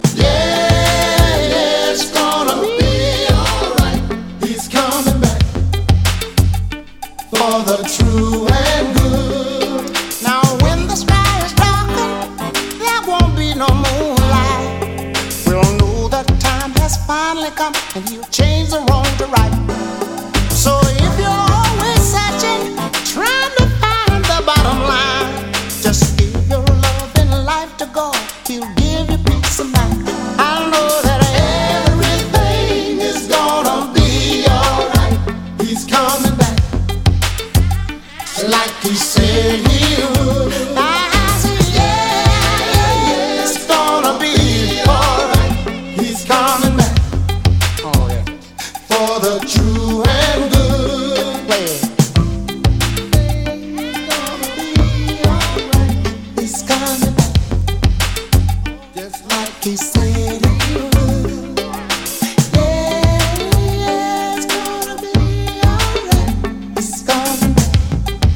ストンピン・リズム＆ブルースなマイナー・ガレージ・ロッキン・コンボ！